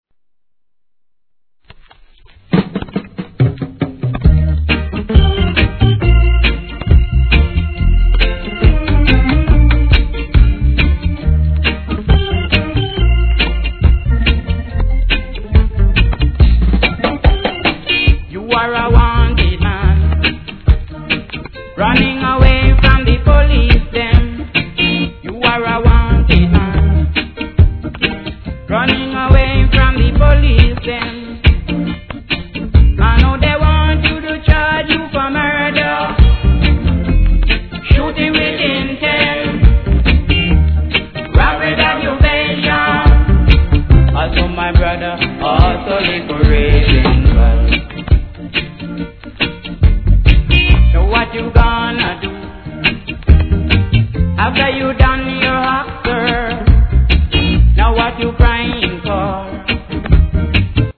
序盤に周期的なプツ
REGGAE